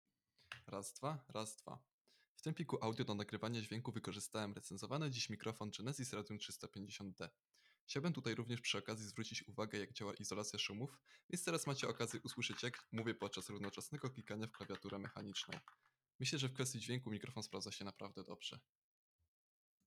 Mikrofon zapewnia czysty, wyraźny i naturalny dźwięk, dzięki zastosowaniu wysokiej jakości kapsuły, która rejestruje pełne pasmo częstotliwości.
Izolacja szumów działa naprawdę bardzo dobrze, co z pewnością docenią osoby siedzące „po drugiej stronie monitora”.
Podczas wideokonferencji i rozmów zdalnych Radium 350D robi naprawdę dobrą robotę – głos brzmi naturalnie, wyraźnie, a komunikacja staje się bardziej komfortowa.